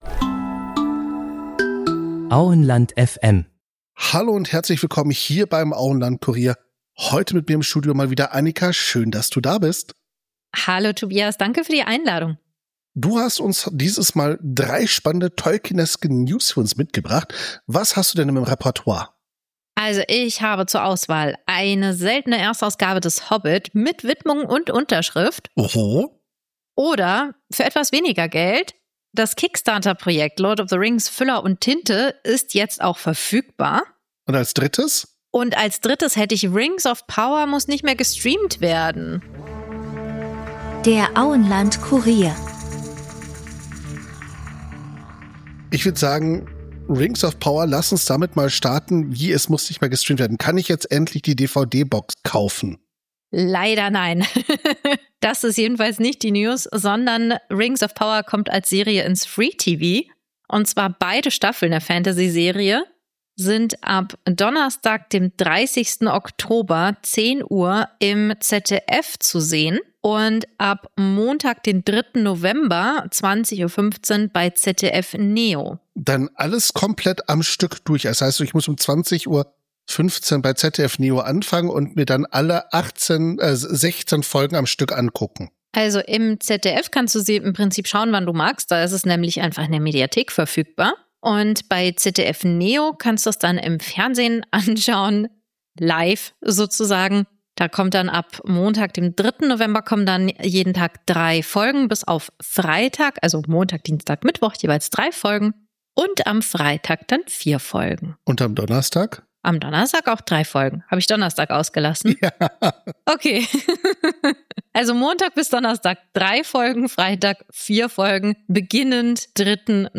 Euer kompakter Nachrichten-Podcast rund um Tolkien!